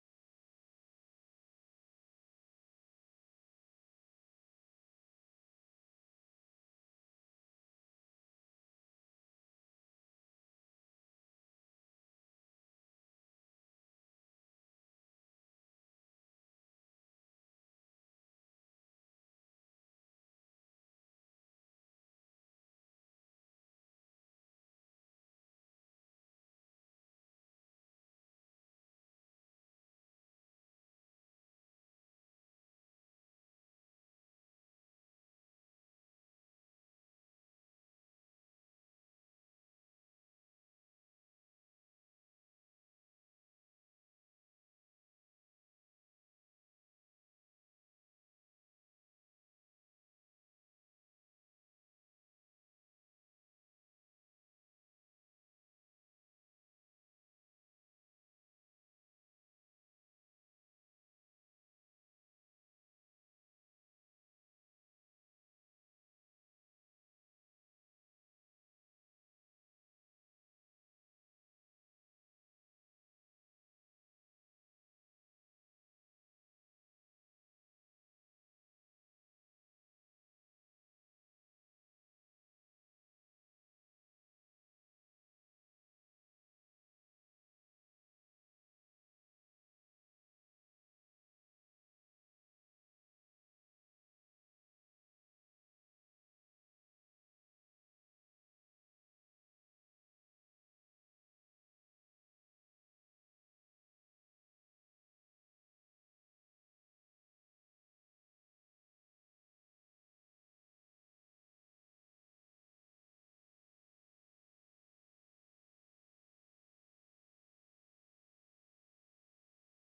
Grace Sermons | Grace Evangelical Free Church
We apologize for the audio issues within the first 6 minutes of the recording.